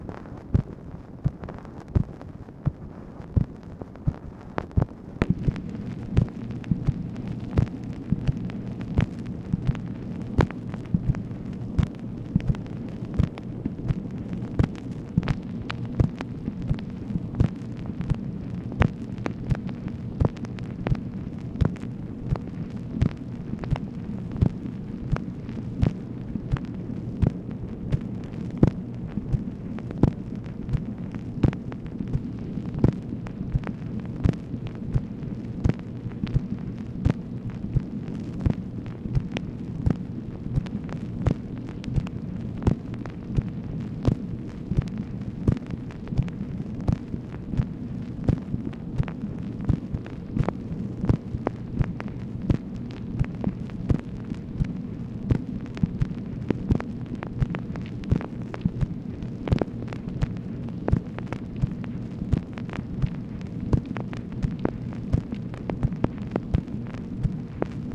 MACHINE NOISE, October 24, 1964
Secret White House Tapes | Lyndon B. Johnson Presidency